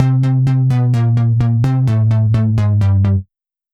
Index of /musicradar/french-house-chillout-samples/128bpm/Instruments
FHC_NippaBass_128-C.wav